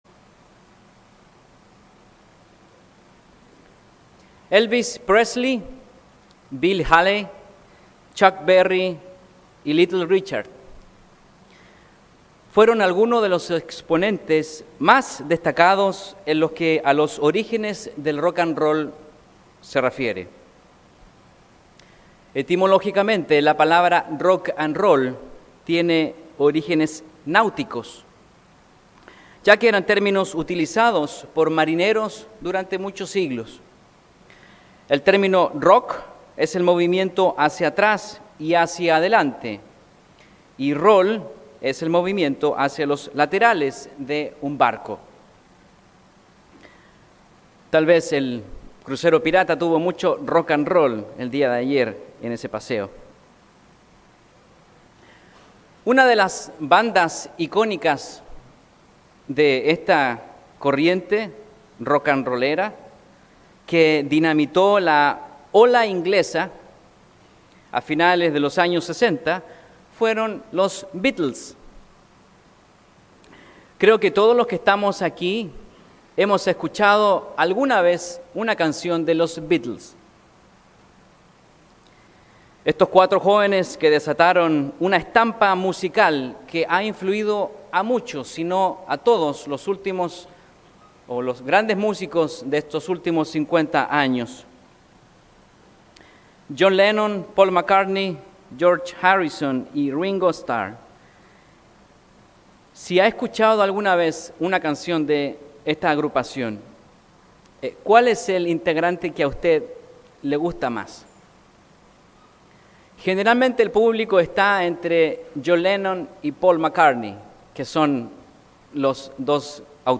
Y como cristianos, nosotros requerimos de una precisión y consistencia similares si deseamos llegar a la meta. Mensaje entregado el 29 de septiembre de 2018.